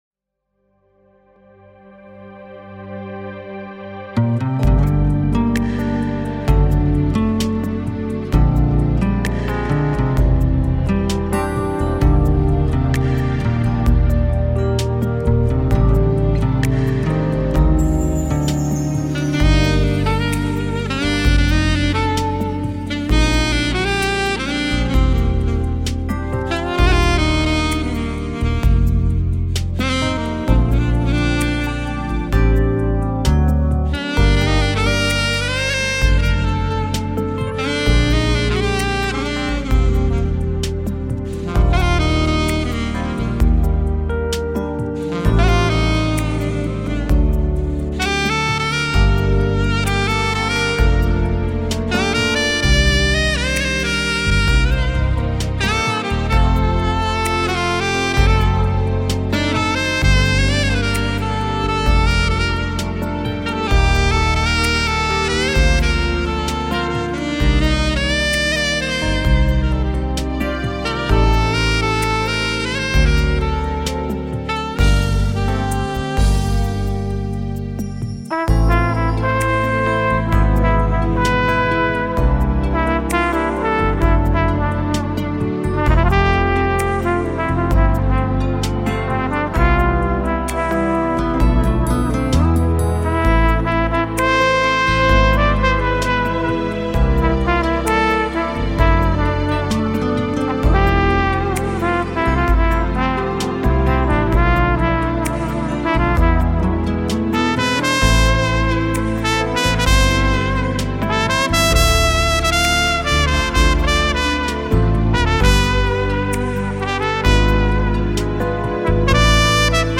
5._fon_dlya_svechi_kompoziciya.mp3